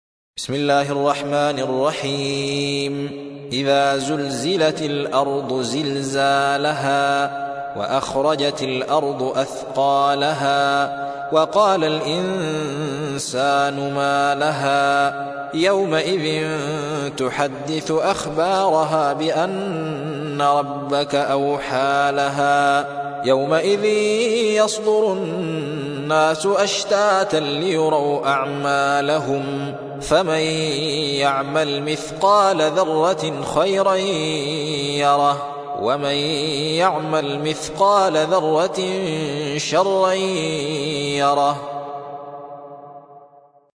99. سورة الزلزلة / القارئ